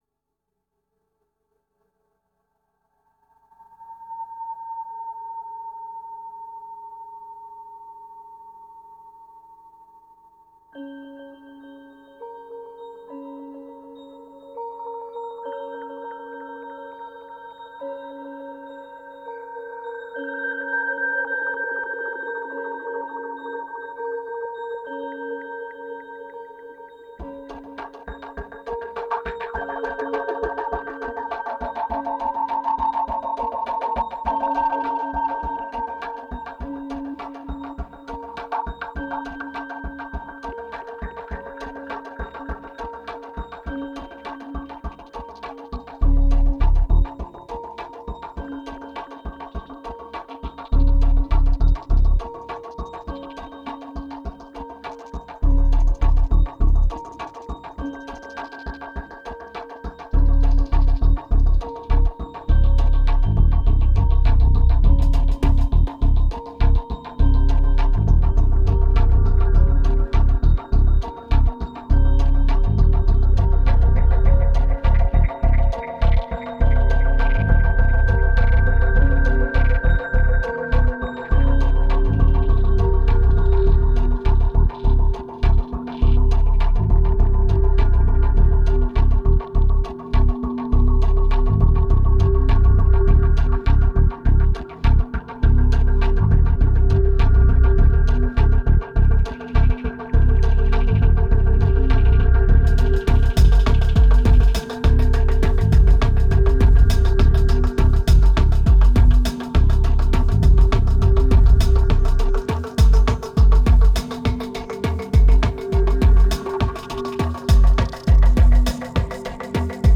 2258📈 - 2%🤔 - 102BPM🔊 - 2010-09-15📅 - -136🌟